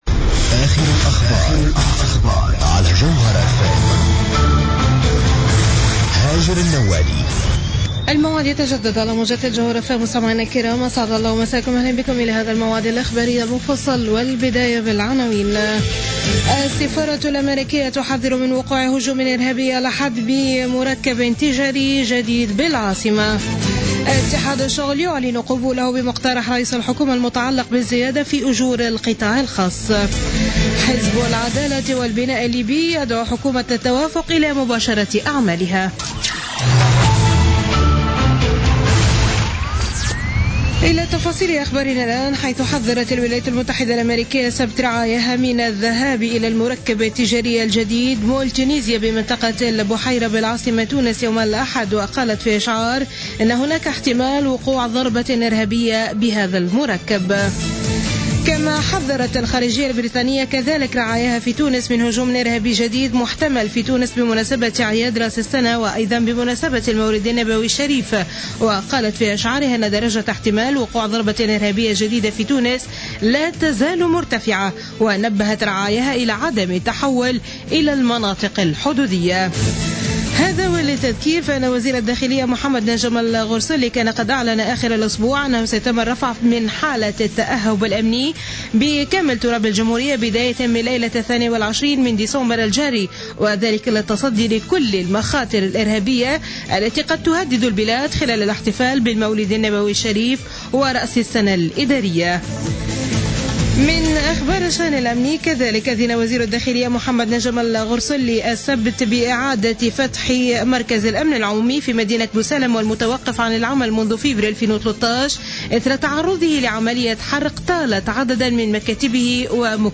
نشرة أخبار منتصف الليل ليوم الأحد 20 ديسمبر 2015